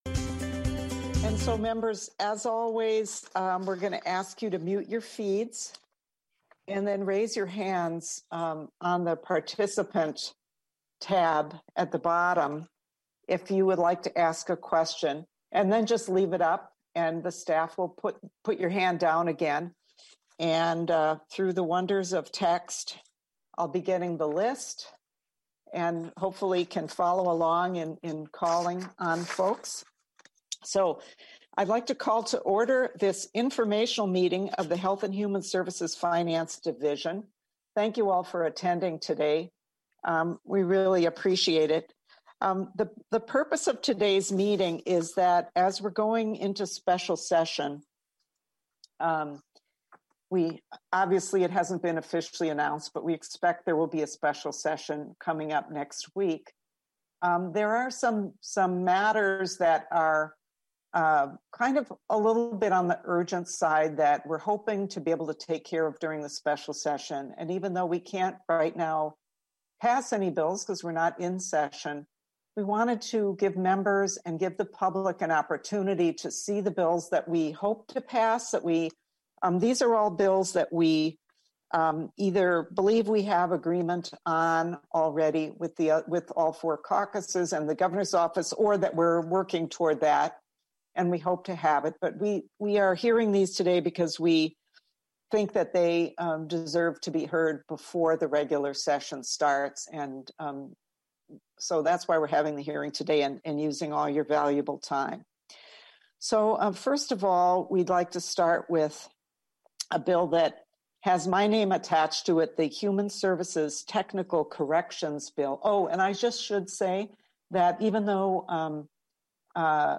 House Ways and Means Committee (Remote Hearing)